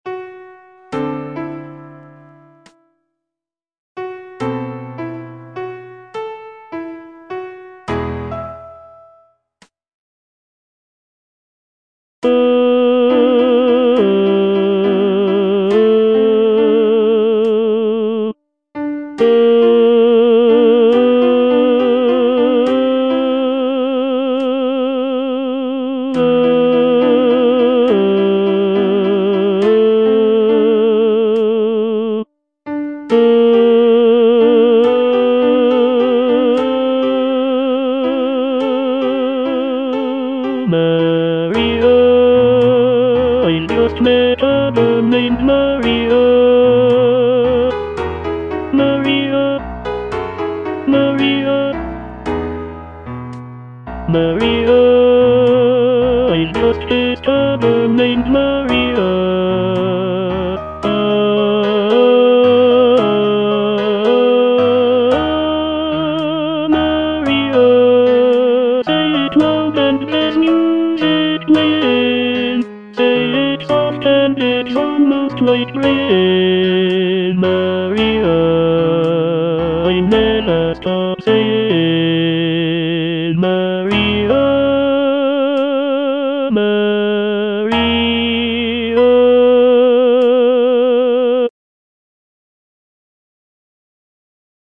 (tenor II) (Voice with metronome) Ads stop